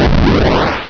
cenotaph/res/sfx/explosion2.wav at main
explosion2.wav